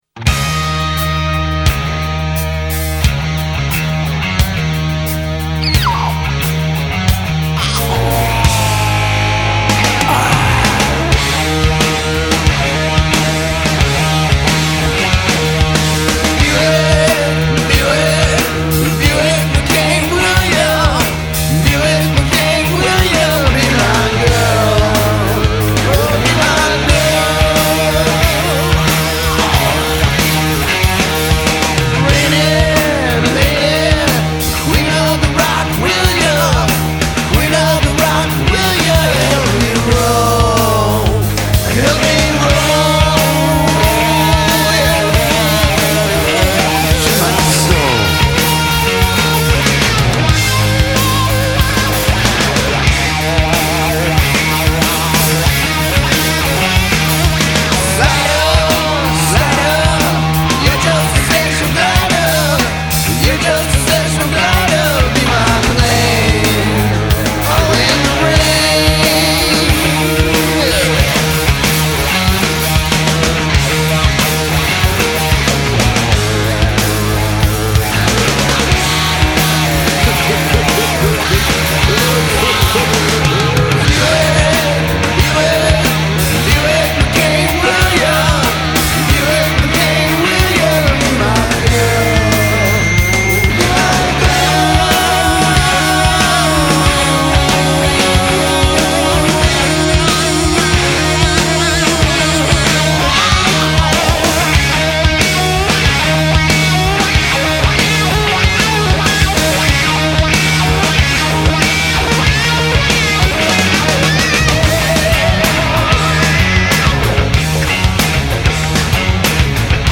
Rock Рок Хард рок Hard Rock